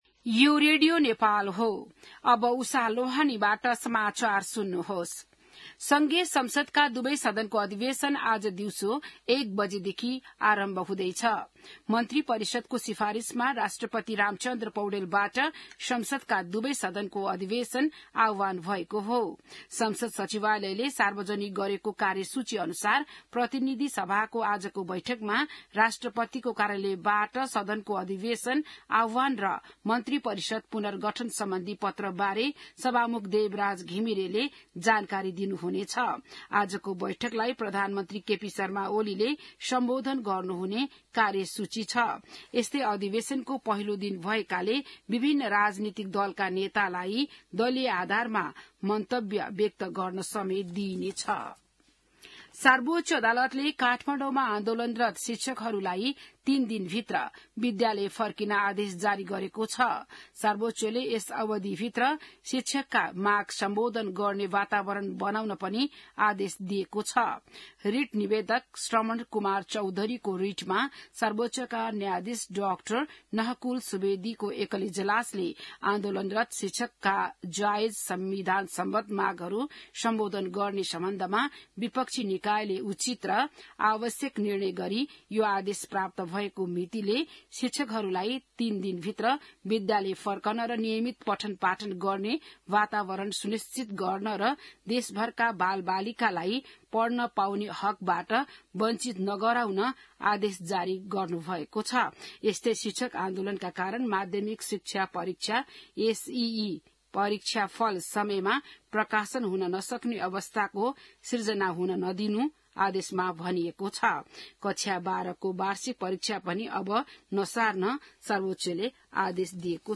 बिहान १० बजेको नेपाली समाचार : १२ वैशाख , २०८२